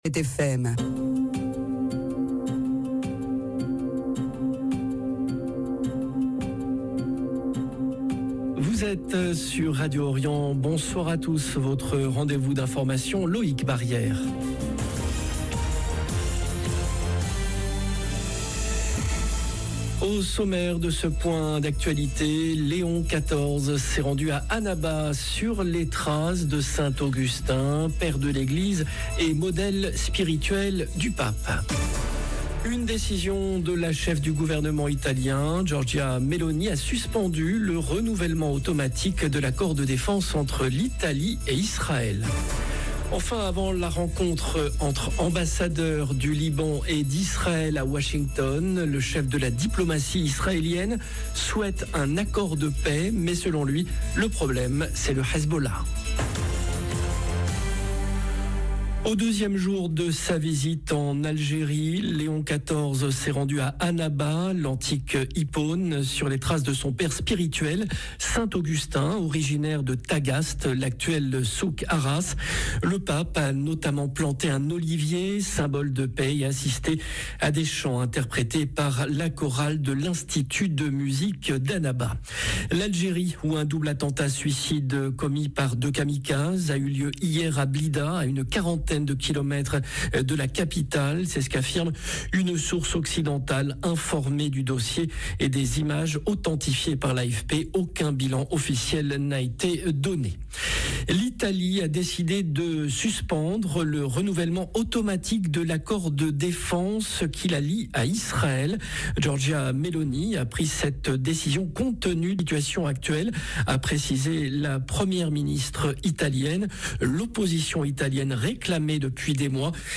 Journal de 17H Au sommaire : Léon XIV s’est rendu à Annaba sur les traces de Saint-Augustin, père de l’Eglise et modèle spirituel du Pape. Une décision de la cheffe du gouvernement italien Giorgia Meloni a suspendu le renouvellement automatique de l’accord de défense entre l’Italie et Israël.